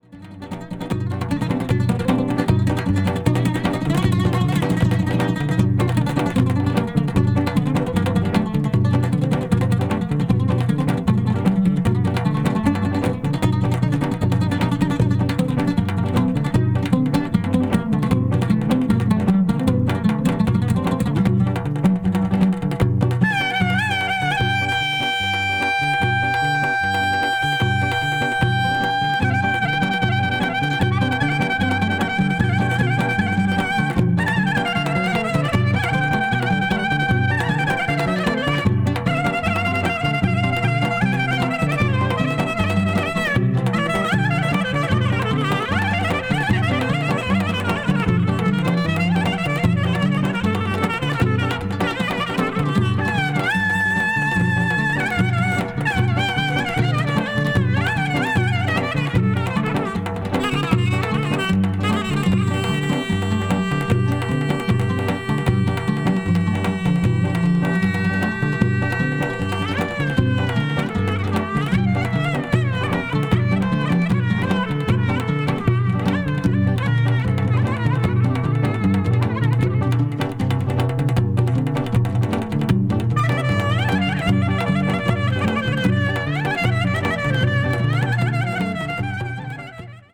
ethnic   folk   greece   psychedelic   world music